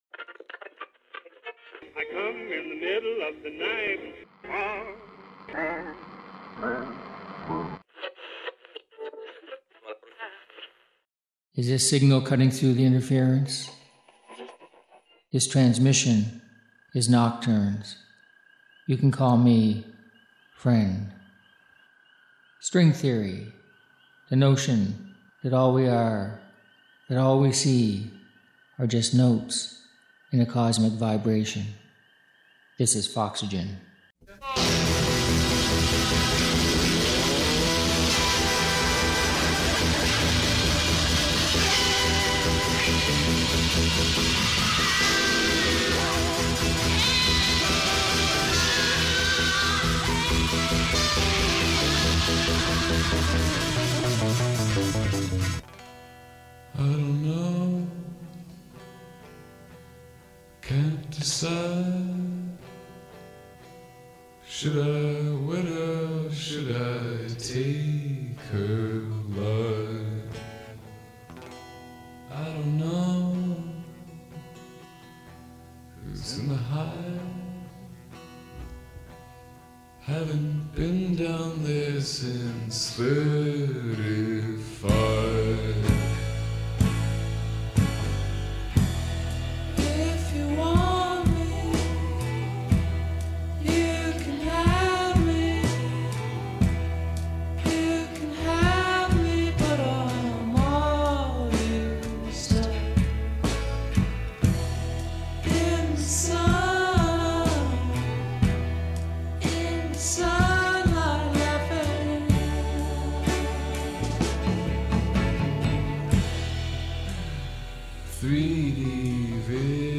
Music for nighttime listening.